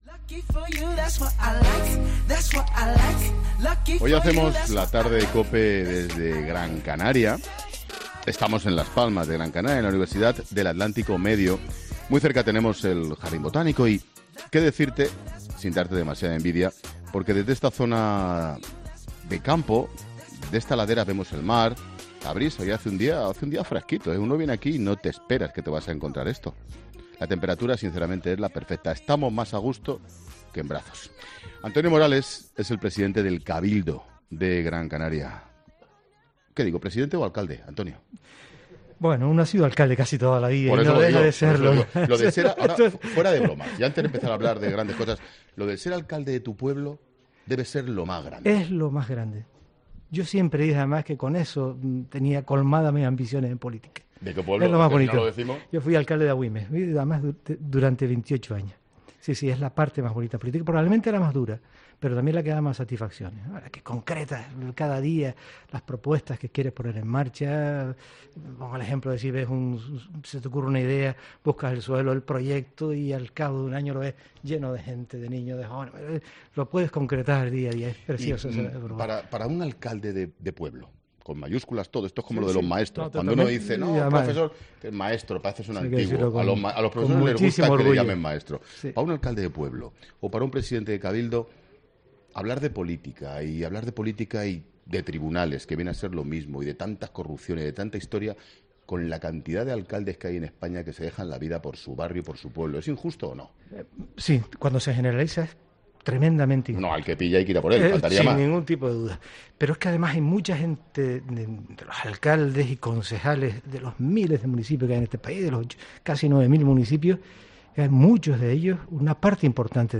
Antonio Morales, presidente del Cabildo de Gran Canaria, ha reconocido en 'La Tarde' que este jueves se ha emitido desde Las Palmas de Gran Canaria,...